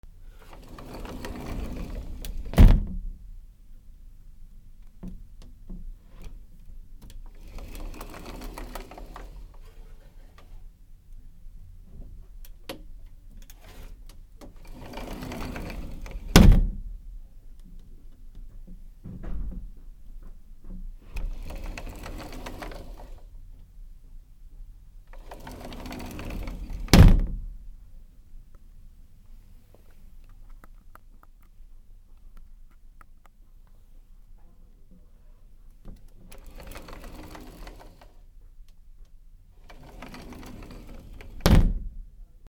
/ K｜フォーリー(開閉) / K20 ｜収納などの扉
『シュルル』